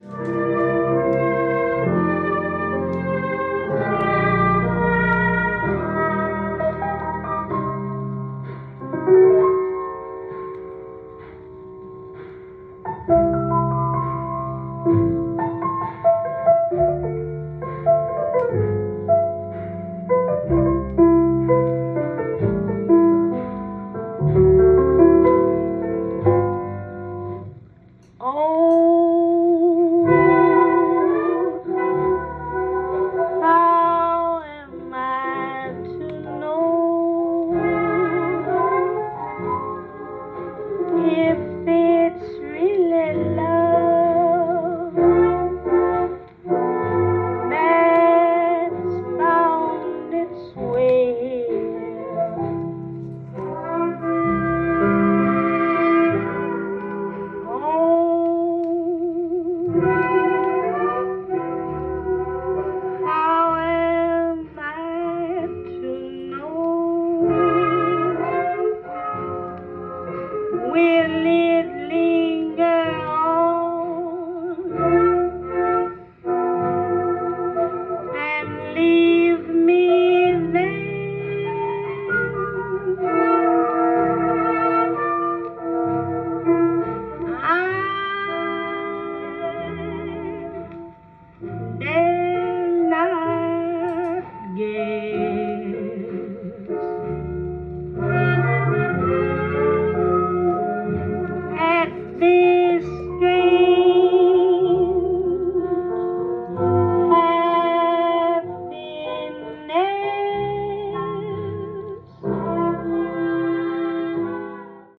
ジャンル：JAZZ-VOCAL
店頭で録音した音源の為、多少の外部音や音質の悪さはございますが、サンプルとしてご視聴ください。